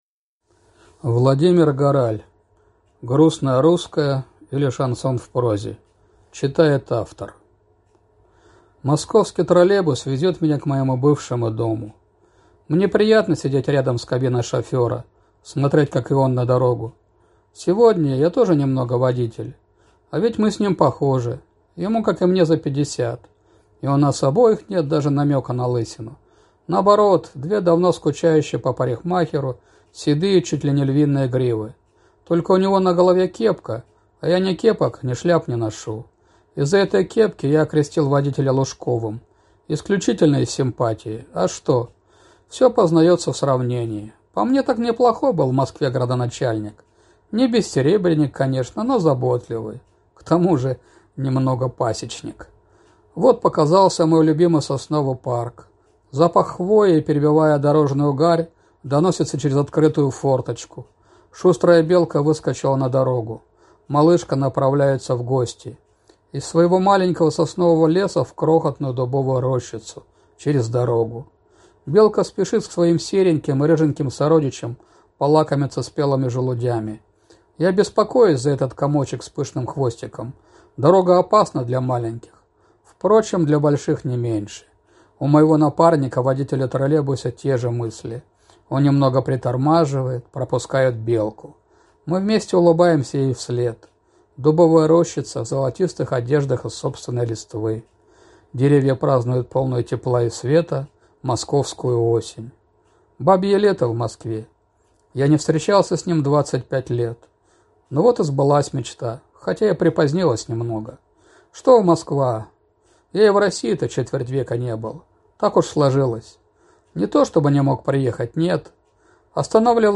Аудиокнига Грустное русское, или Шансон в прозе…